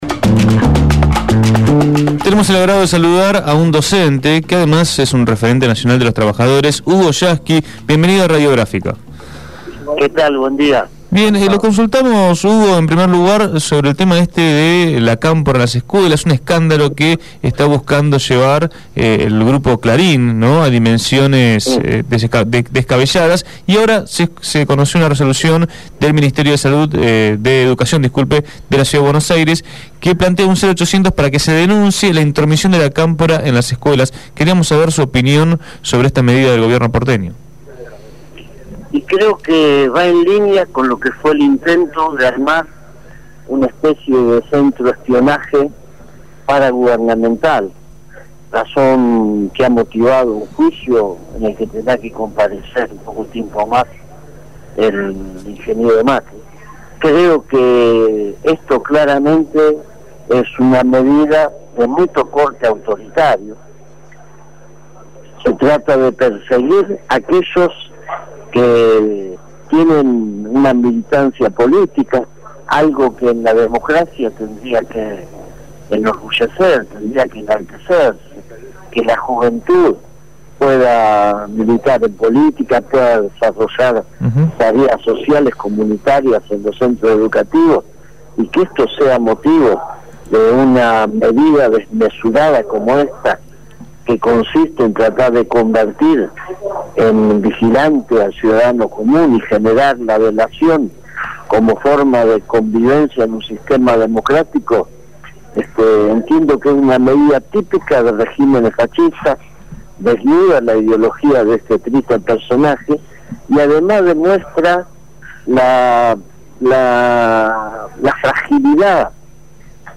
Hugo Yasky, Secretario General de la CTA, habló en Punto de Partida.